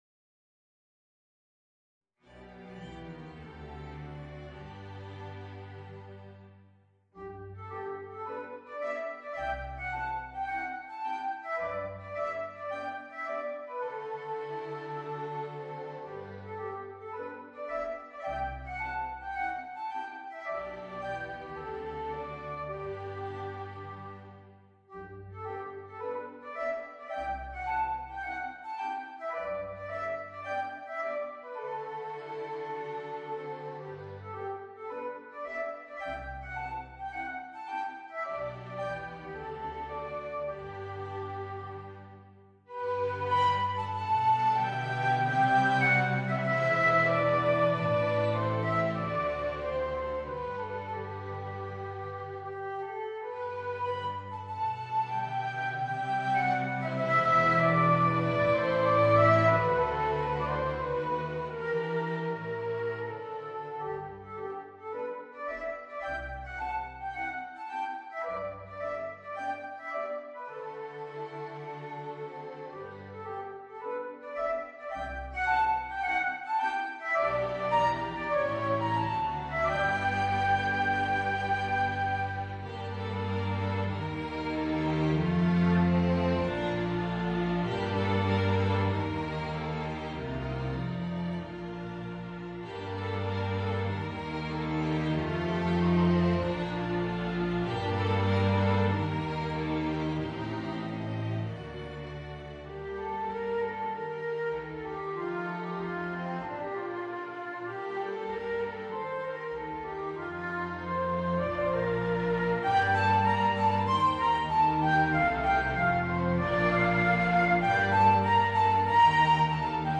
Voicing: Viola and String Quintet